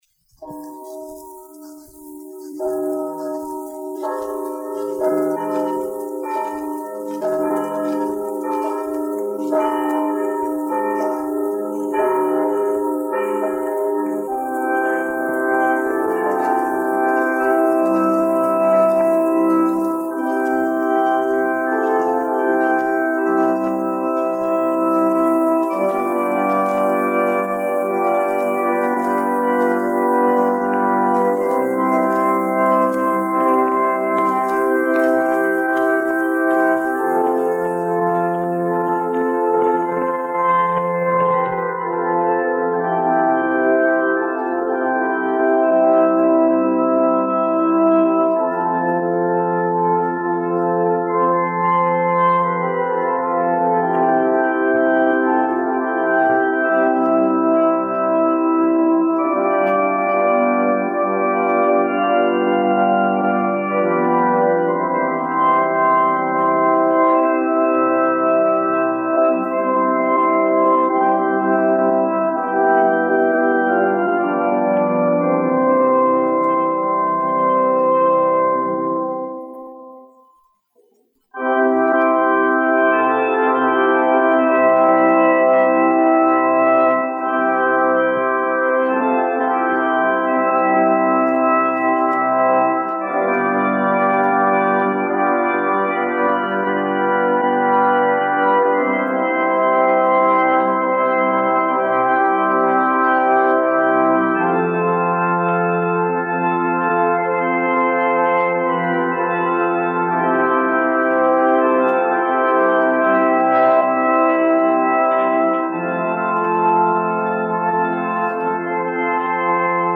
1 skpl. : analogs, 78 apgr/min, mono ; 25 cm
Ziemassvētku mūzika
Ērģeļu mūzika
Skaņuplate